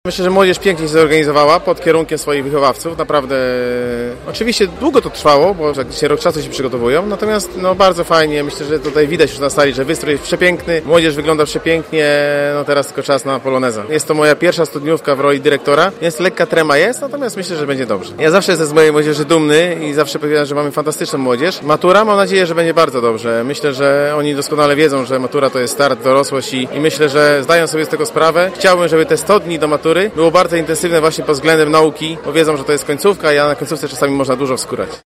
Tradycyjnym Polonezem maturzyści z Zespołu Szkół Gastronomicznych w Gorzowie, rozpoczęli swoją długo wyczekiwaną studniówkę, którą uczniowie zorganizowali przy pomocy rodziców i nauczycieli.